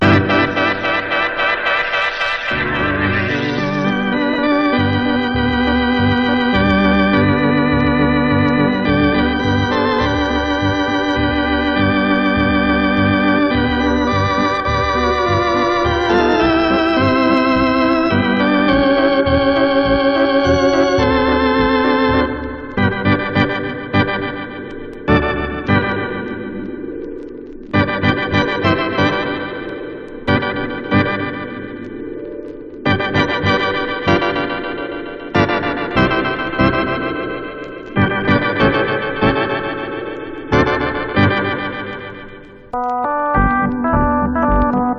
小編成でゆったりとリラックスしつつ、巧みな演奏が嬉しい1枚です。
Jazz, Easy Listening　USA　12inchレコード　33rpm　Mono